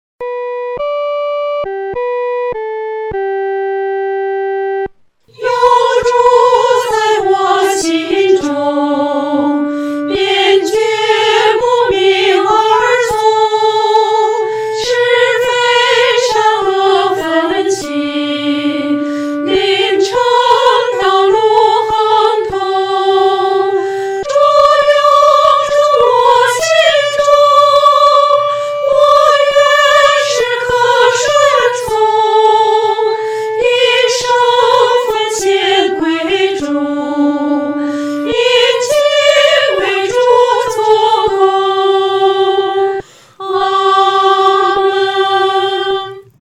合唱
女高
诗班在二次创作这首诗歌时，要清楚这首诗歌音乐表情是亲切、温存地。